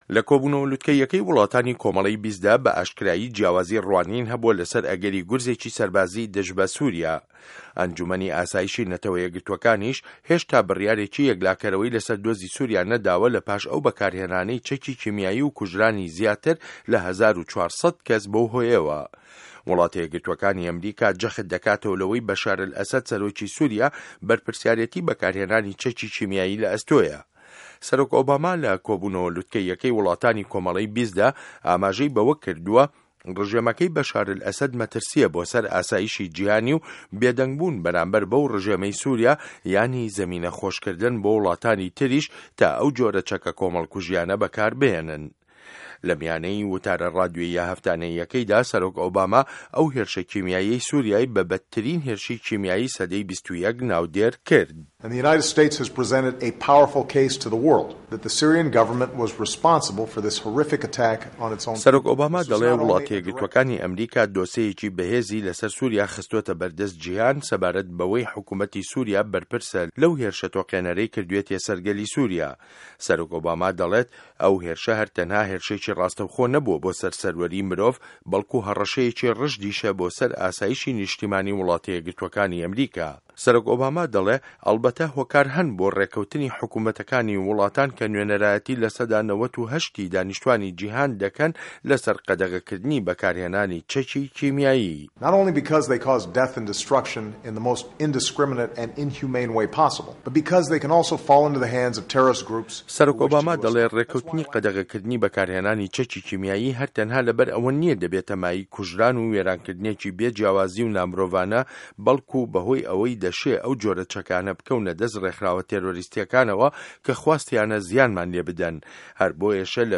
له‌ وتاره‌ ڕادیۆییه‌ هه‌فتانه‌یه‌که‌یدا سه‌رۆک براک ئۆباما هێرشه‌ کیمیاییه‌که‌ی سوریای به‌ به‌دترین هێرشی کیمیایی سه‌ده‌ی 21 ناودێرکرد.